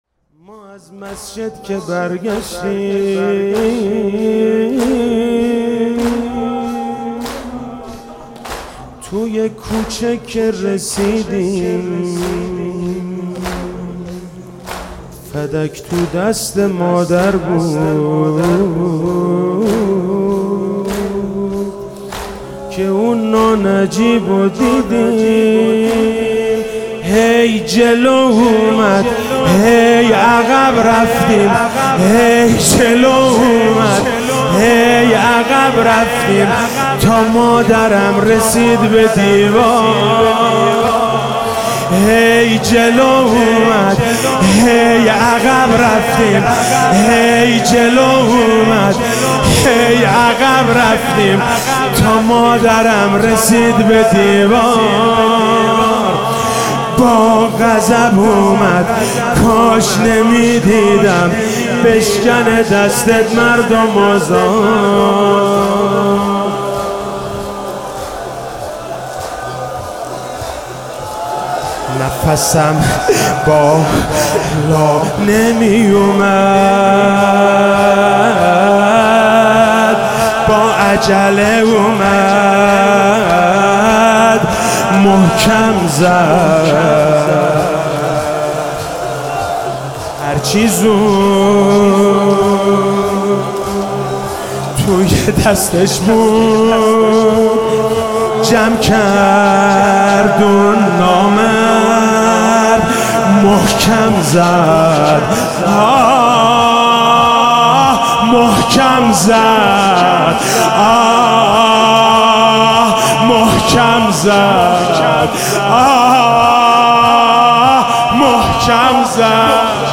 حسینیه ی انصارالحجة